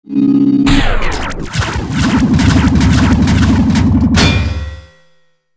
dig.wav